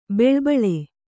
♪ beḷpḷe